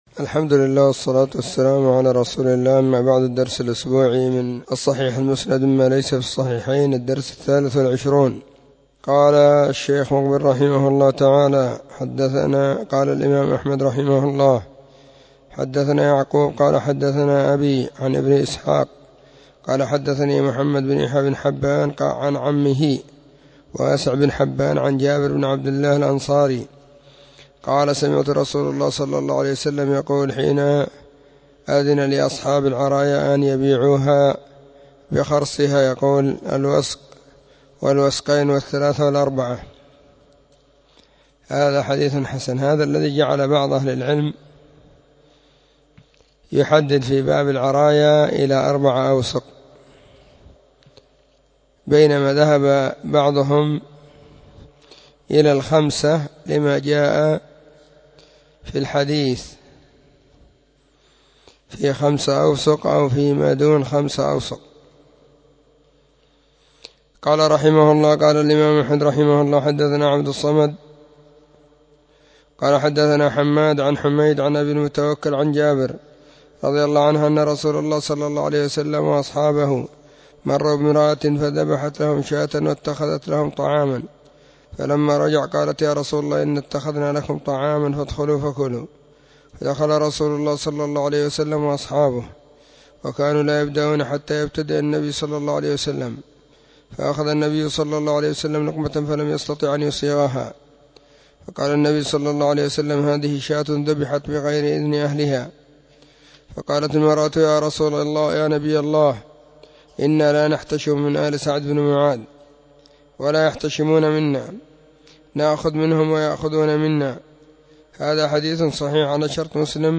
خميس -} 📢مسجد الصحابة – بالغيضة – المهرة، اليمن حرسها الله.
الصحيح_المسند_مما_ليس_في_الصحيحين_الدرس_23.mp3